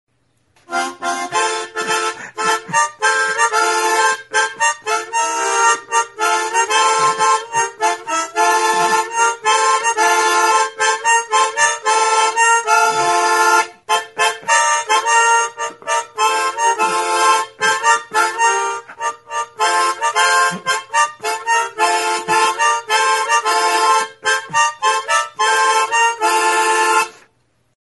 Aerophones -> Reeds -> Single Free
Oiartzun
Recorded with this music instrument.
Armonika arrunta da. 20 mihi ditu, 10eko bi lerrotan kokaturik. Fa tonalitatean dago.